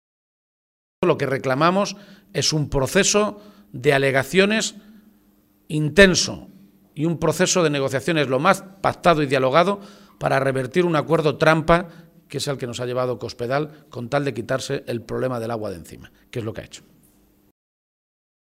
Emiliano García-Page durante la rueda de prensa celebrada en Talavera
Cortes de audio de la rueda de prensa